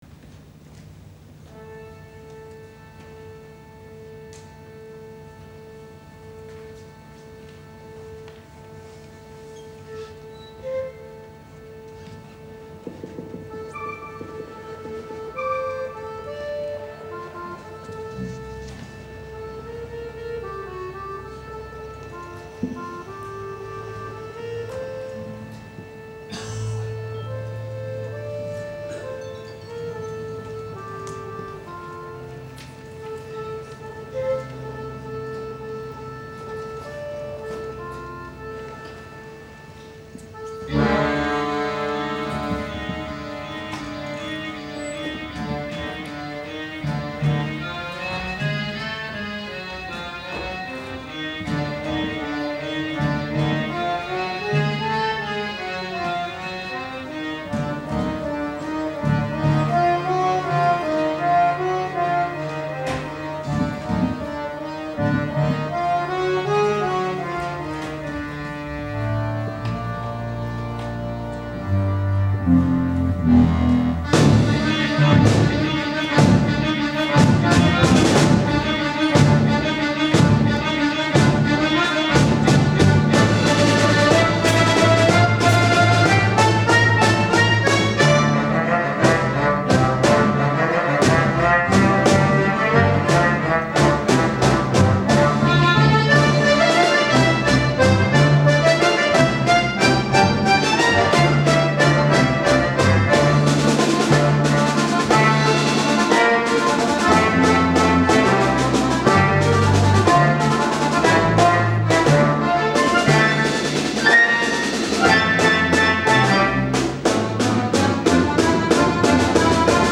1.Akkordeon-Orchester Rheinhausen 1950 e.V.
Nach dem großen Erfolg des 1.Filmkonzertes im Oktober 2008 folgte am 26.April 2009 ein Wiederholungskonzert.
Es fand in der Rheinhausenhalle statt.
Orchester 1
K.Badelt, H.Zimmer